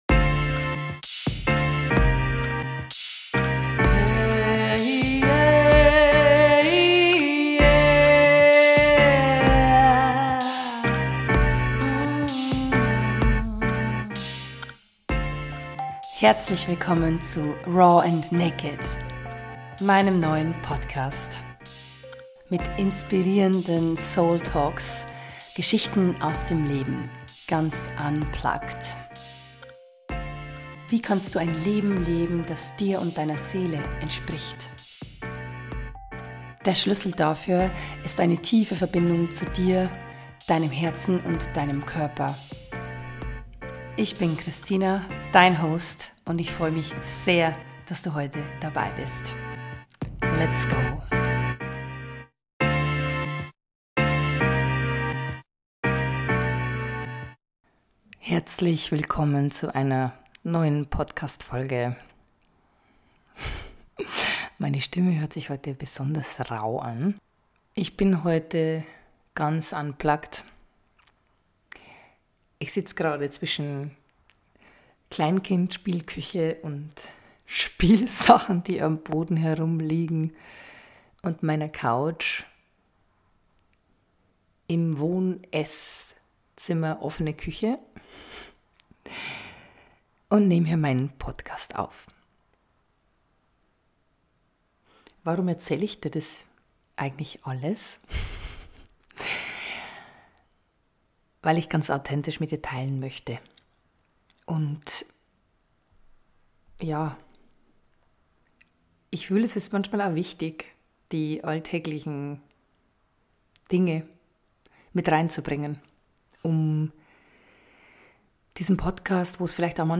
Juli 2023 Nächste Episode download Beschreibung Kapitel Teilen Abonnieren In dieser Folge geht teile ich mit euch meine Erfahrungen und Learnings aus meinen teils heftigen PMS-phasen. Und ab Minute 9:30 gibt es eine Körpermeditation.